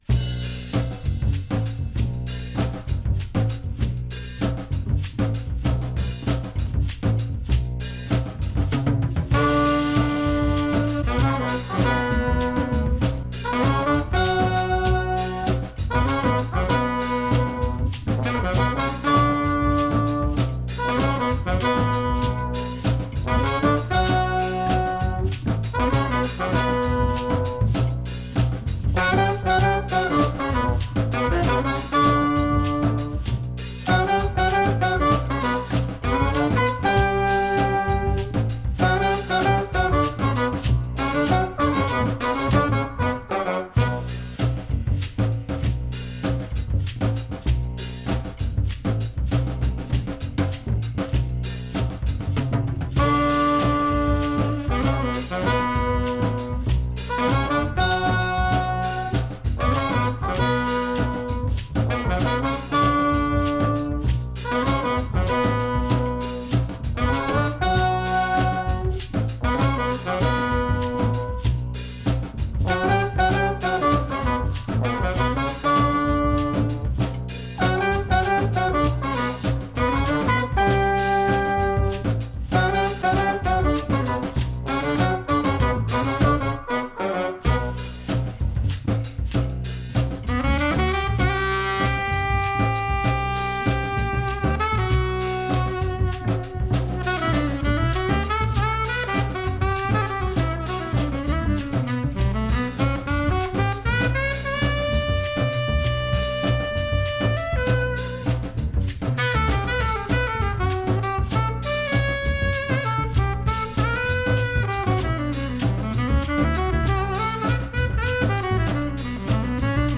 Recorded at the Maid’s Room, NYC
trombone
trumpet
alto saxophone
drums & percussion
Stereo (Pro Tools)